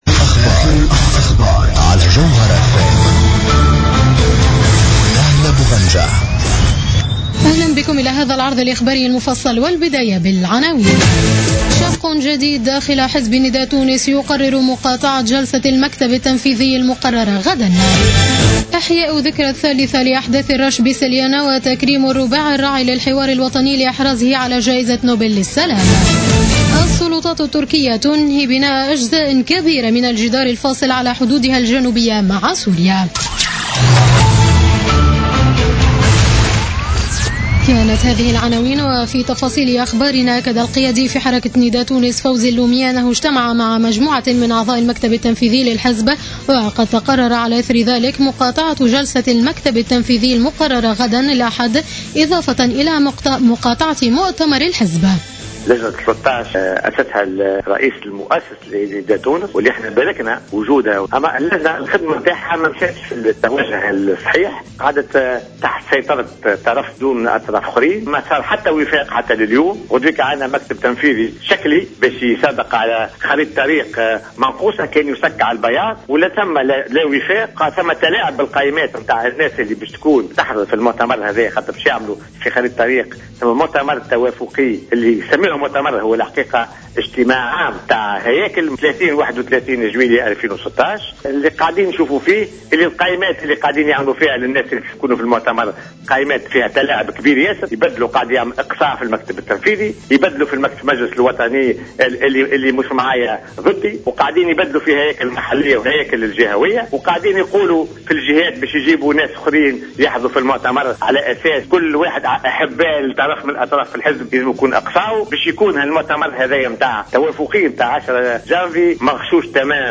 نشرة أخبار السابعة مساء ليوم السبت 26 ديسمبر 2015